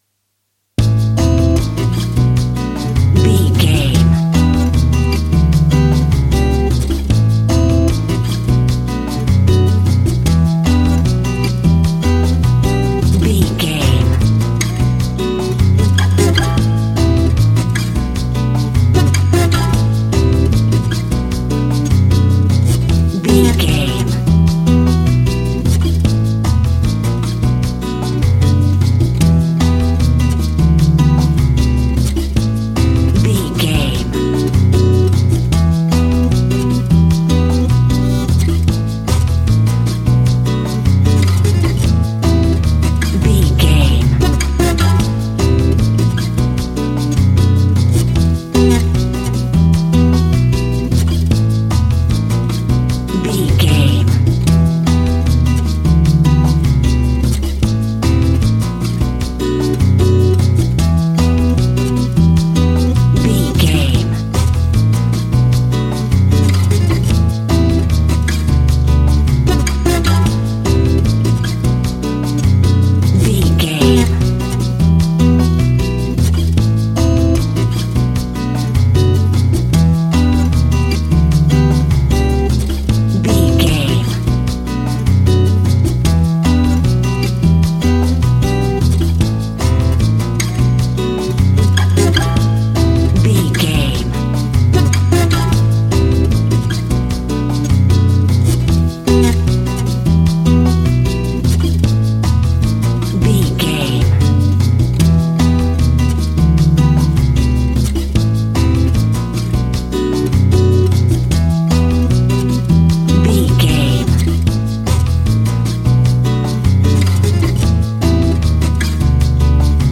Aeolian/Minor
flamenco
maracas
percussion spanish guitar